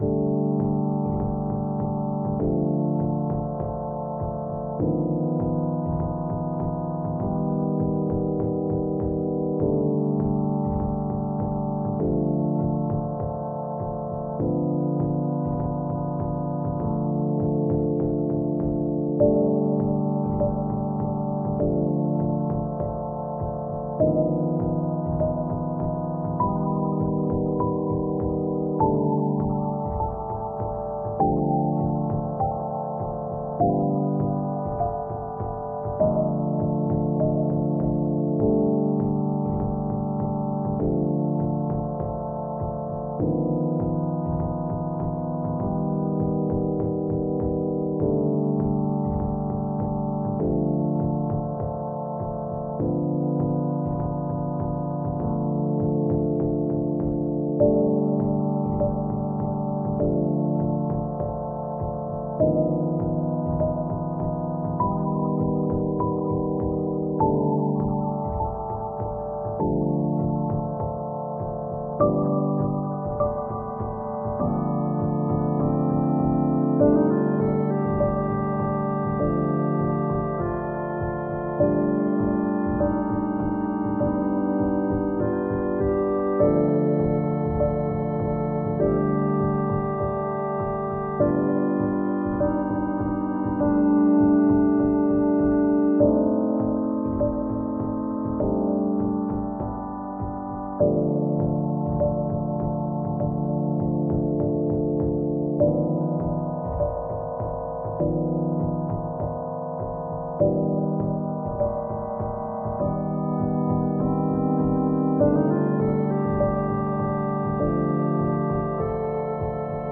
Washed in reverb, bitcrushed with a bandpass filter. I wanted something that sounded dreamy and dangerous.
Instead of a bitcrush plugin I just reduced the sample rate in audacity.
synth spooky tense